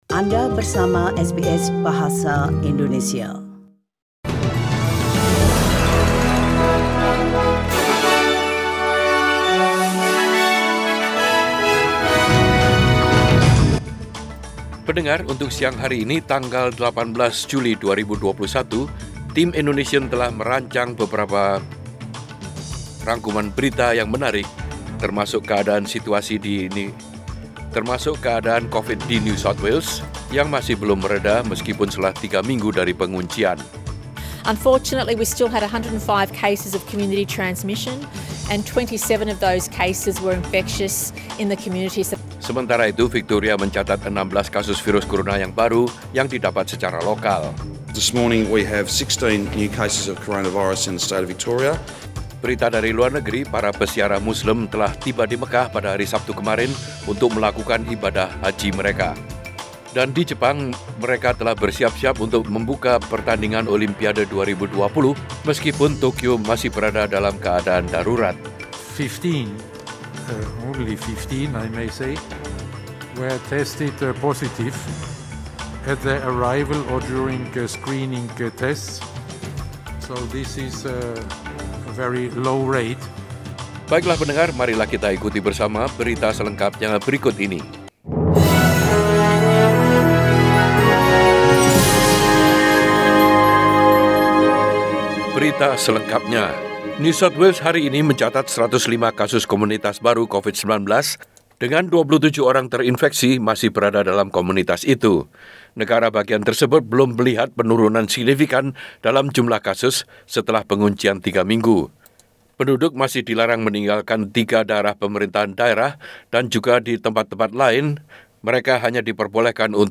Warta Berita Radio SBS Program Bahasa Indonesia.